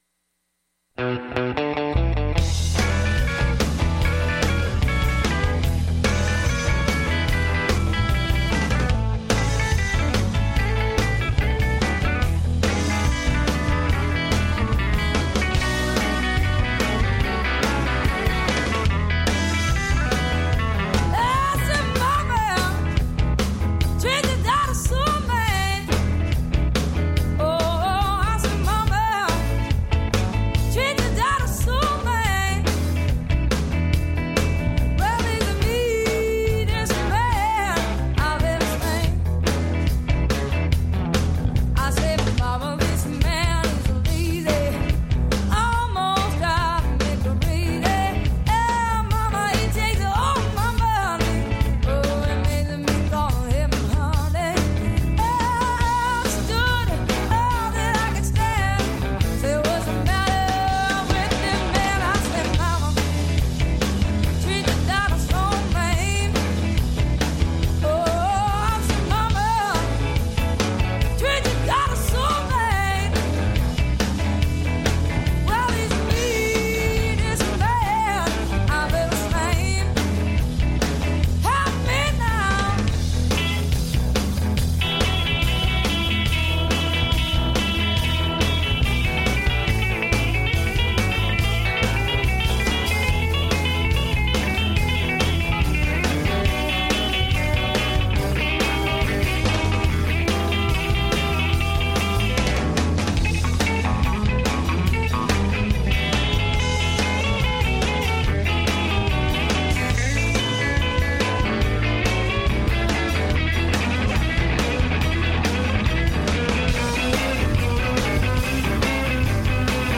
Mother’s Day set of music
Mama Blues